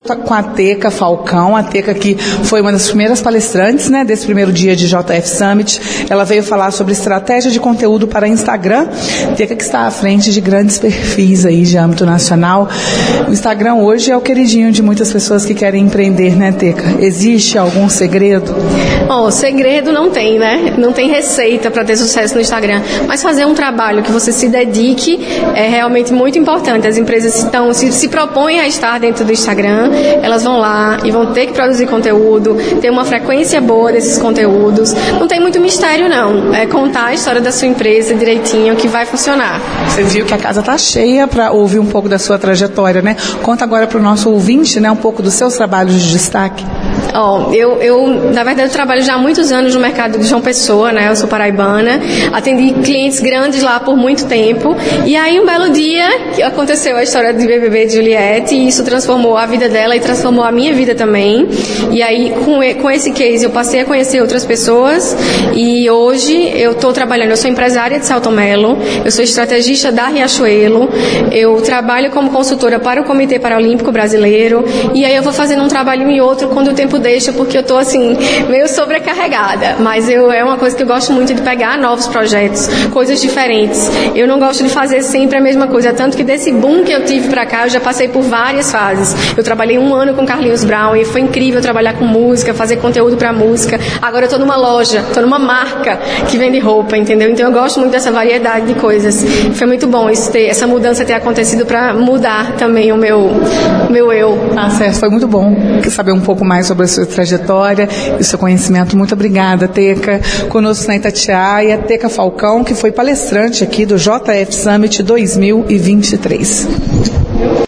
A reportagem da Itatiaia acompanhou a primeira tarde do evento e trouxe ao longo da programação as opiniões e avaliações de participantes.